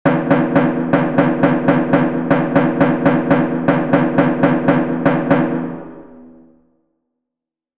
• KOPANITZA: Ritmo de los Balcanes (Bulgaria) en 11/8
Audio de elaboración propia. Patrón rítmico Kopanitza. (CC BY-NC-SA)